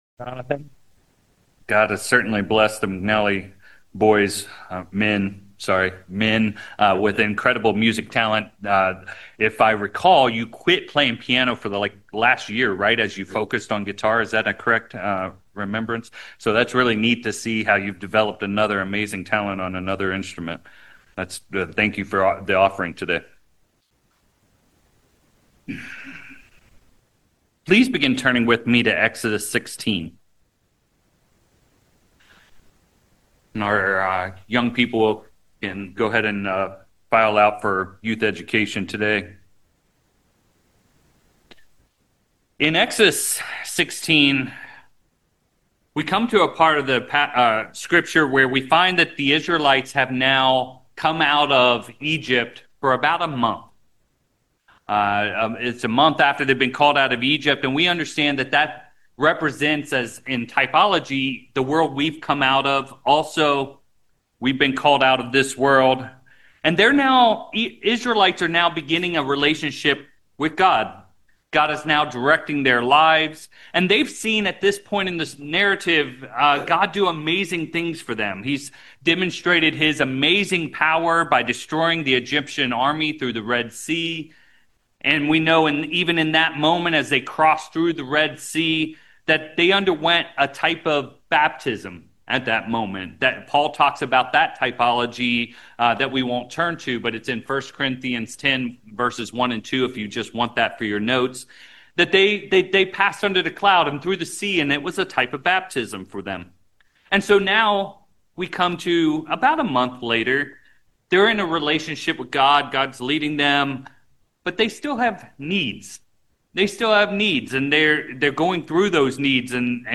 Sermons
Given in San Francisco Bay Area, CA San Jose, CA